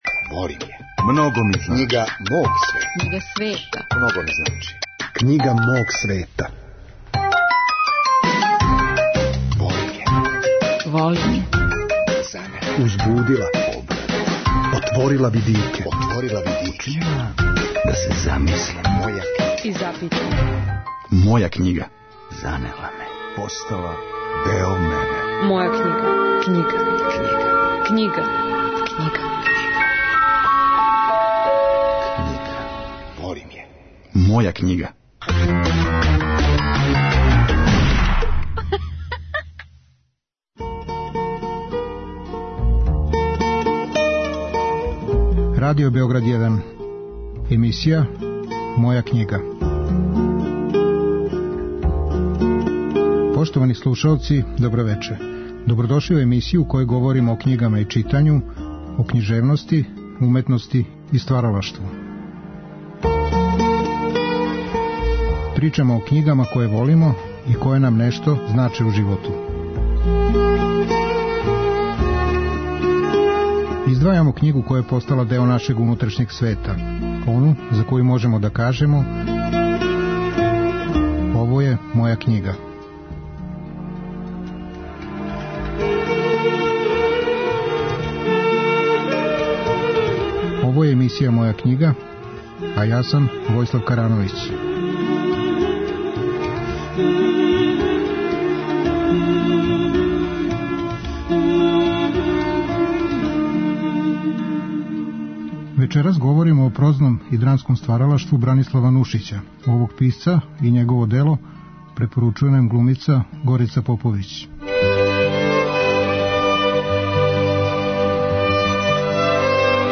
У емисији ћемо чути и делове текстова Бранислава Нушића, који су објављени у Политици под псеудонимом Бен Акиба, у интерпретацији Горице Поповић.